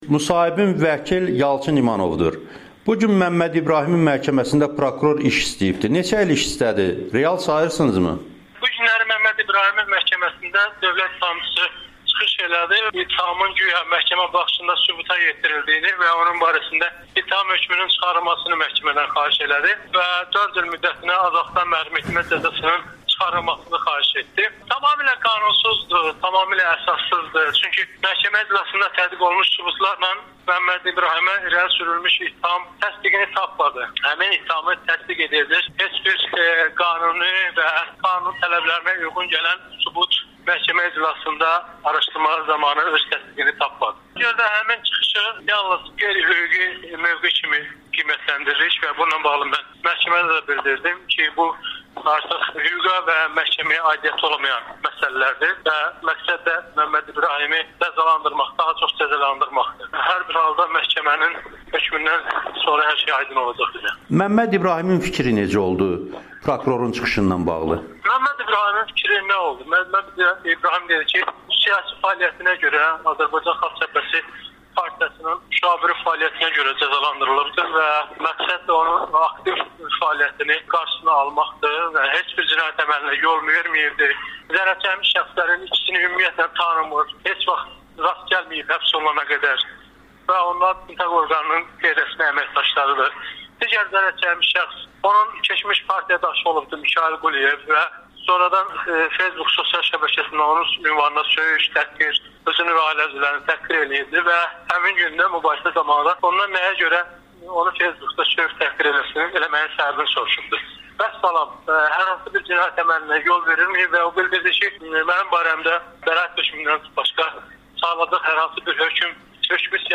[Audio-Müsahibə]